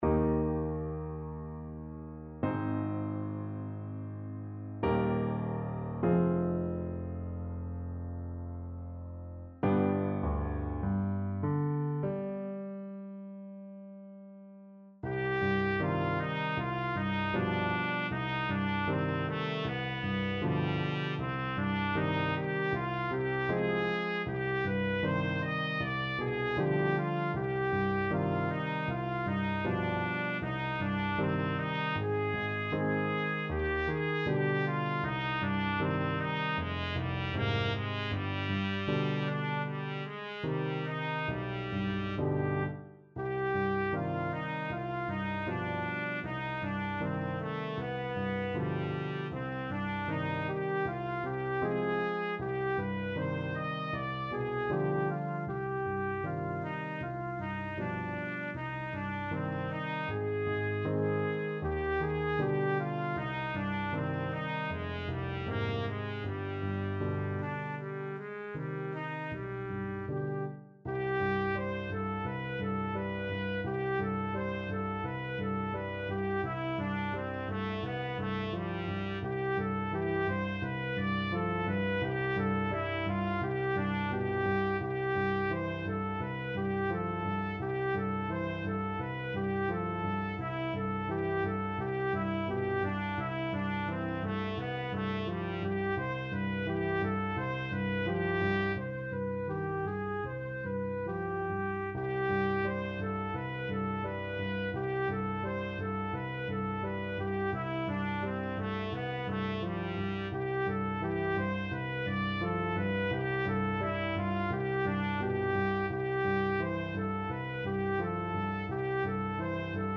Trumpet version
Largo
4/4 (View more 4/4 Music)
Classical (View more Classical Trumpet Music)